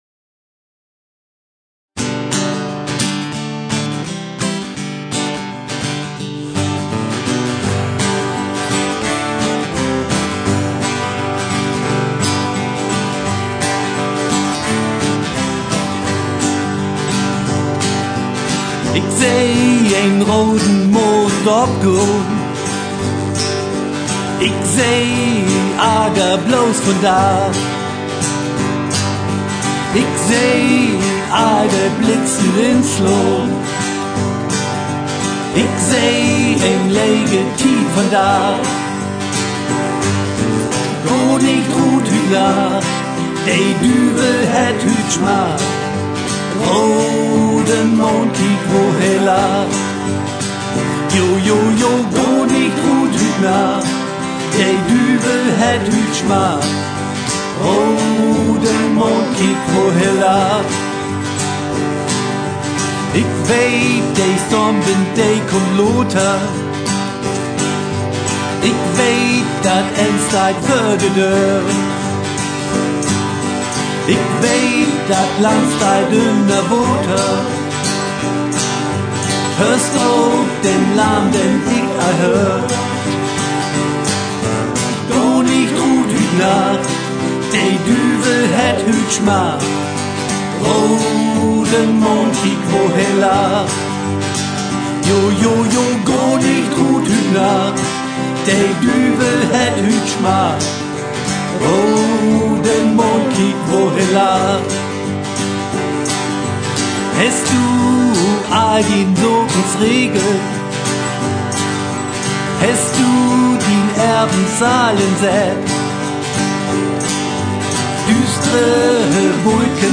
aus der Studio CD